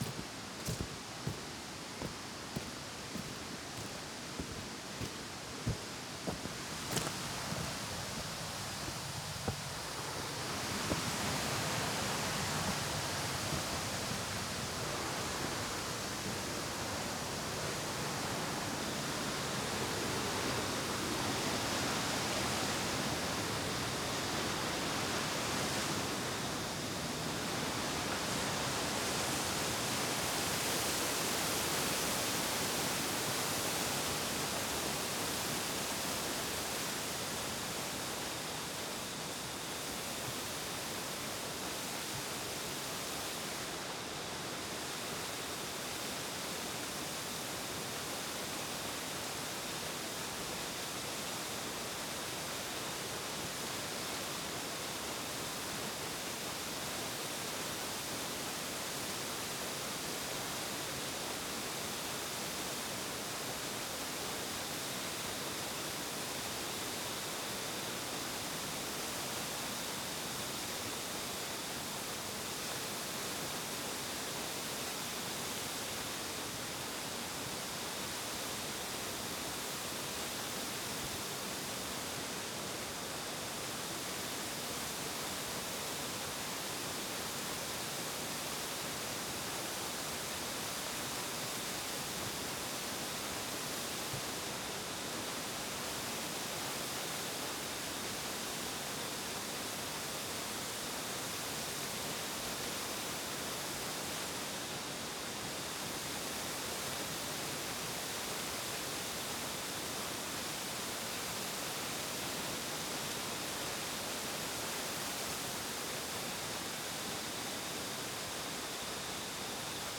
滝の音シリーズ
白く薄いカーテンが風にたなびく美しくも強く流れる滝の音で安らぐココロにマイナスイオンをさらに注入し、ココロの解放でスッ・キリ！の響き（normal sound）